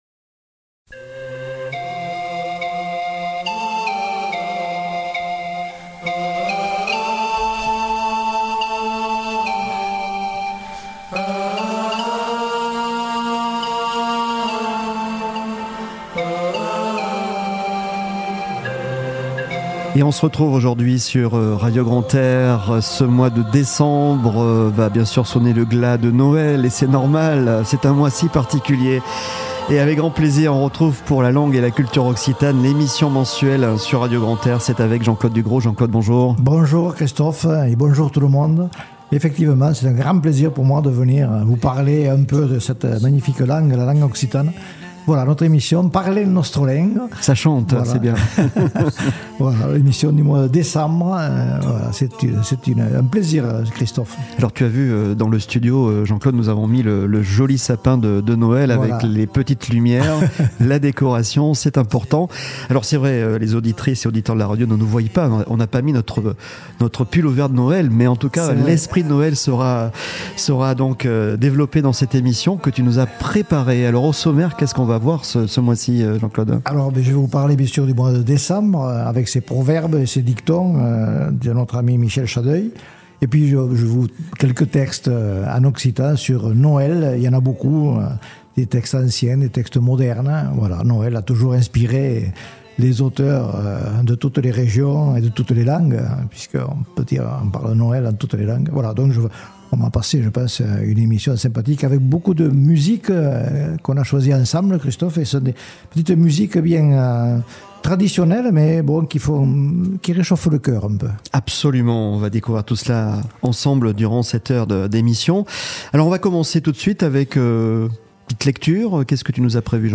Éphéméride du mois avec l'almanach de Michel CHADEUIL, proverbes et dictons de Décembre ! Des histoires et des textes anciens et modernes en Occitan Les nouveautés du mois Avec des musiques traditionnelles de Noël !